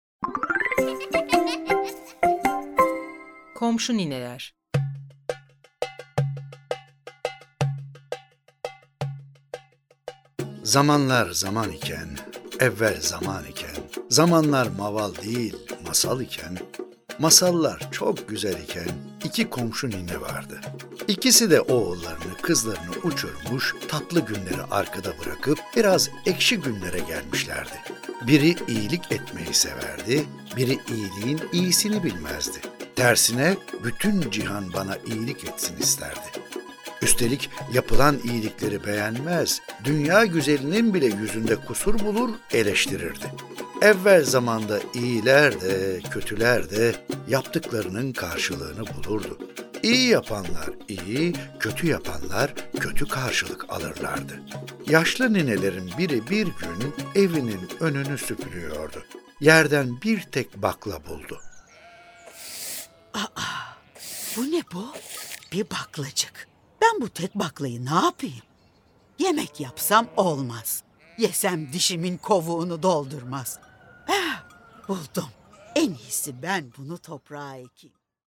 Komşu Nineler Tiyatrosu